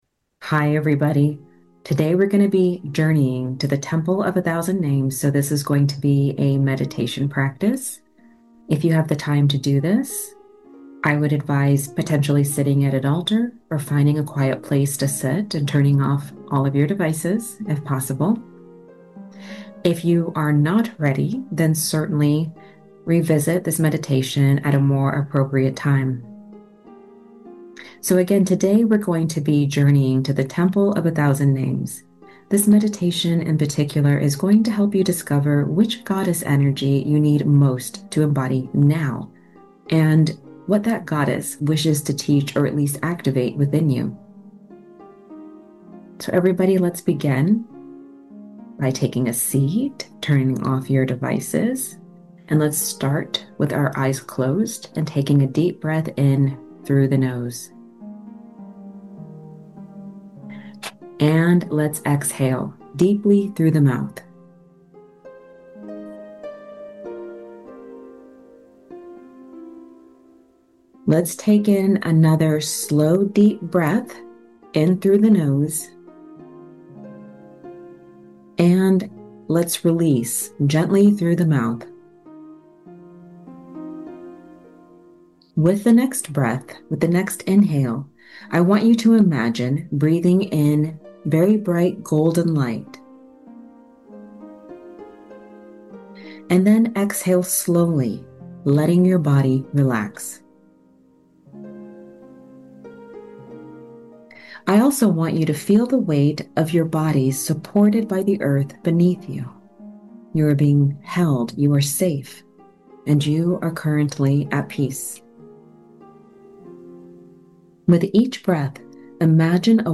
The Temple of a Thousand Names: A Short Meditation